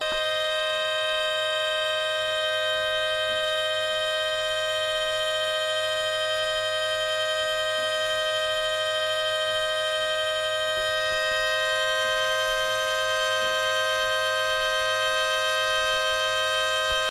Dog Whistle
Dog whistle super high pitch audio sample that will make you sick to your stomache and have a headache if you listen to it for too long.
Very very very annoying.